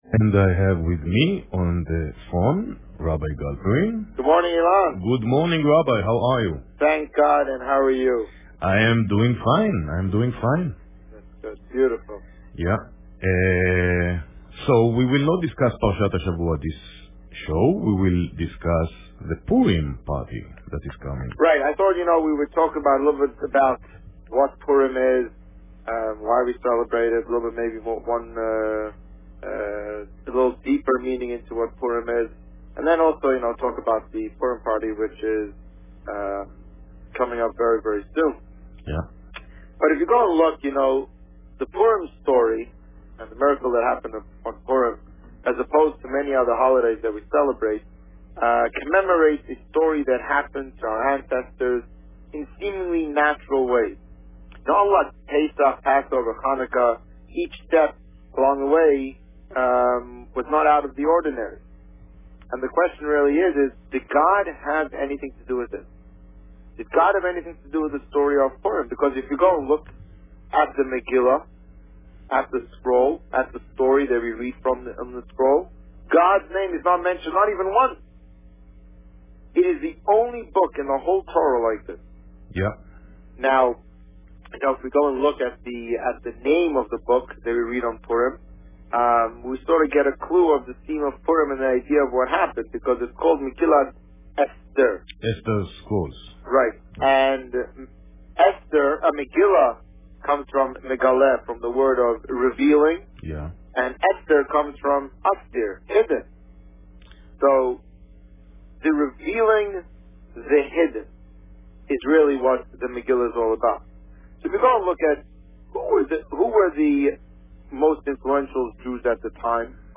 The Rabbi on Radio
The meaning of Purim, and the upcoming Purim party - 2014 Published: 06 March 2014 | Written by Administrator On March 6, 2014, the Rabbi spoke about the meaning of Purim and the upcoming Purim festivities. Listen to the interview here .